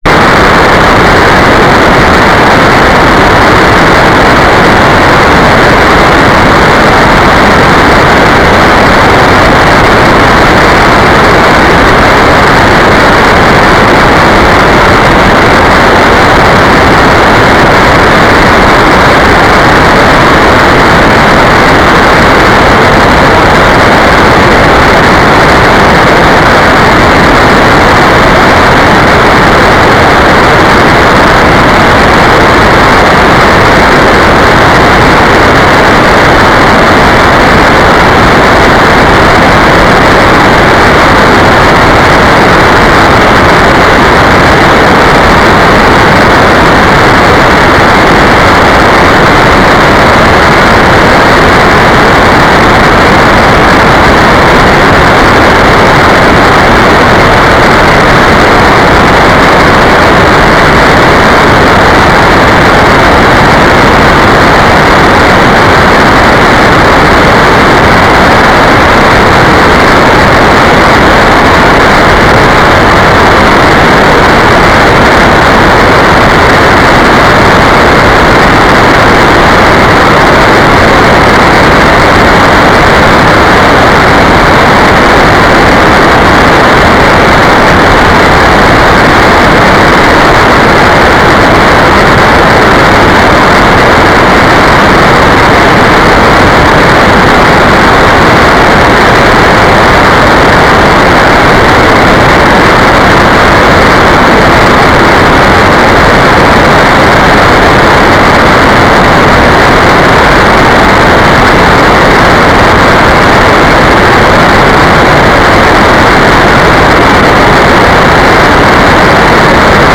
"transmitter_description": "Mode U TLM 2k4 GMSK AX25",
"transmitter_mode": "GMSK",